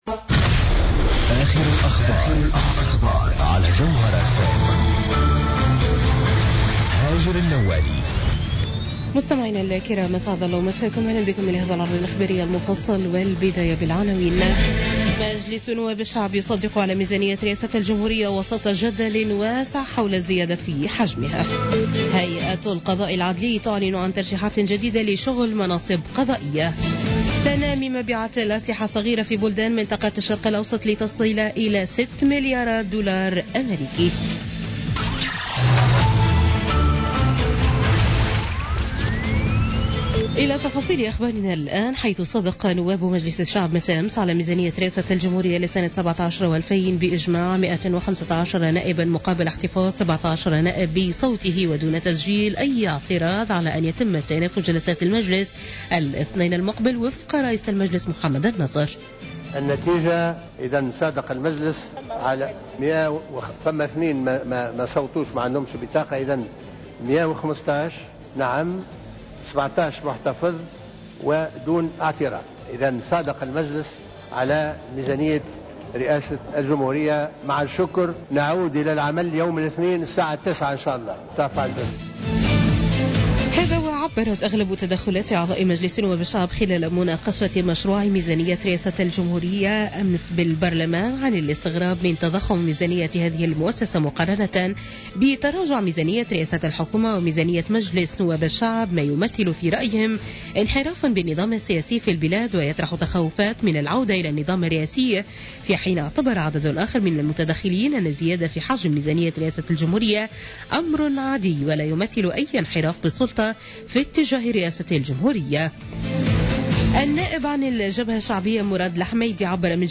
نشرة أخبارمنتصف الليل ليوم الأحد 20 نوفمبر 2016